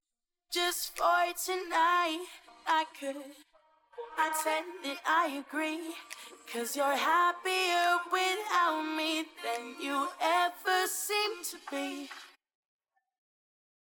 【ステレオソングからボーカルを分離】